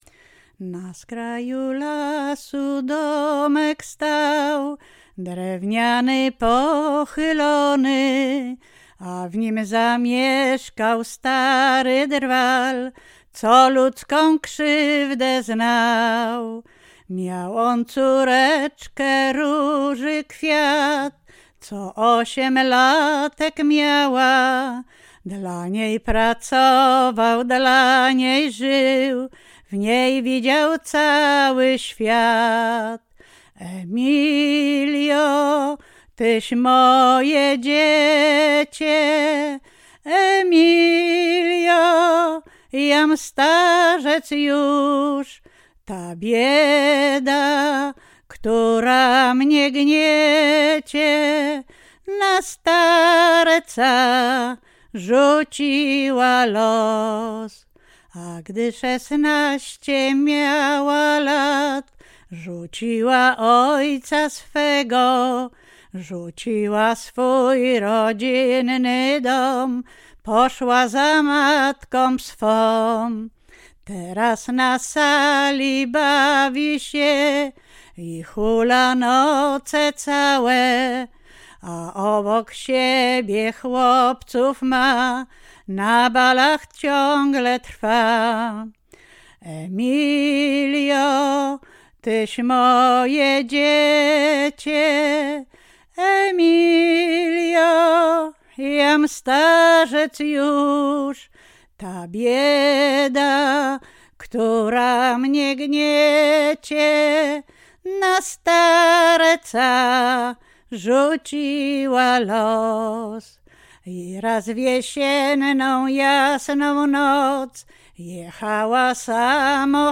Łęczyckie
liryczne ballady pieśni piękne